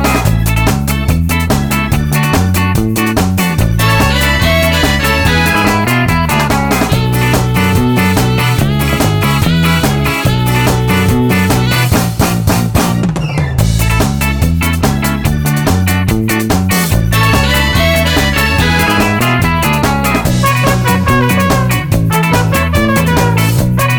no Backing Vocals Ska 2:48 Buy £1.50